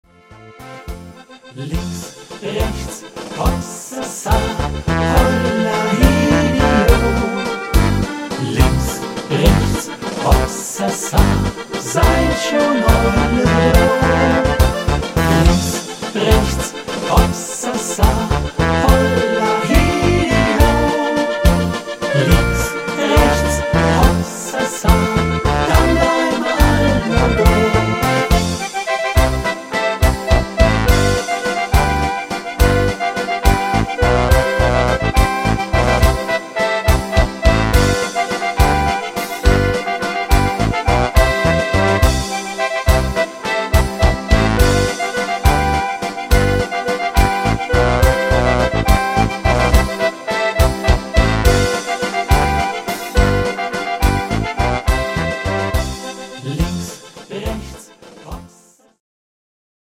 Schunkelwalzer für gute Stimmung